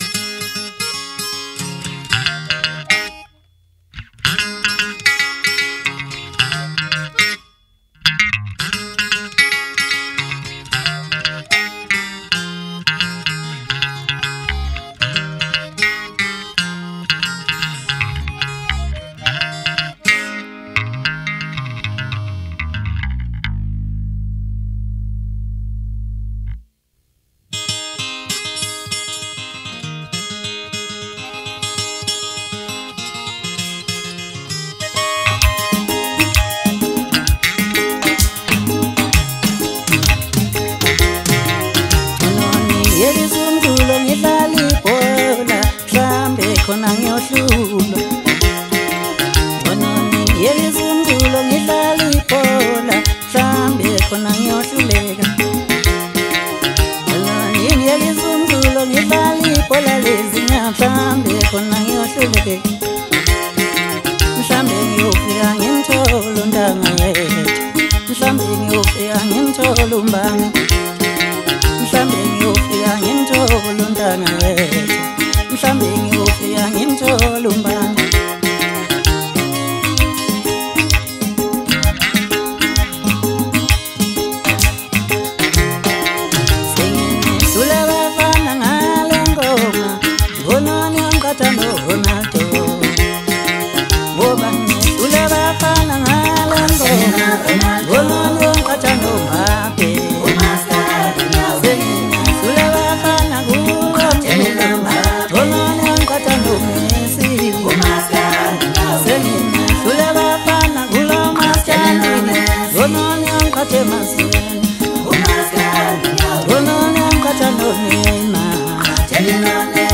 Home » Maskandi » Maskandi Music
Maskandi Songs